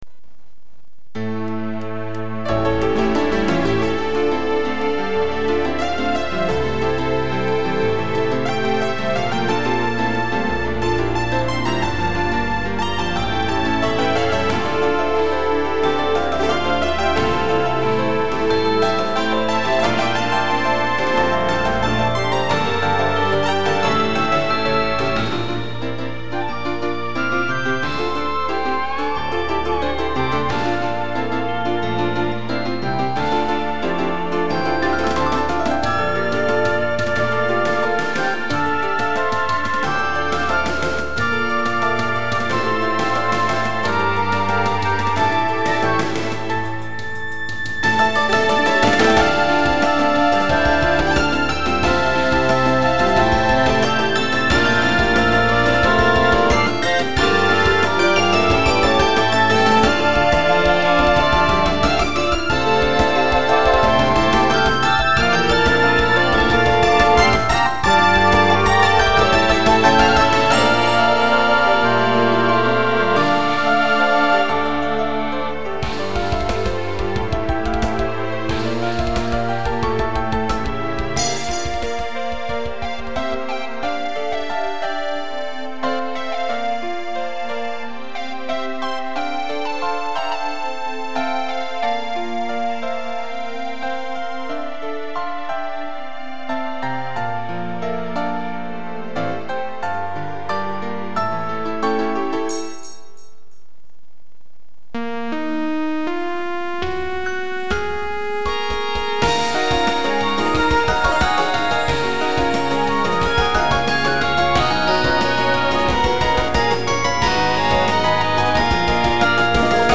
Fire of Fury 怒り。 でも底を覗くと、なぜか悲しみが見えたりする。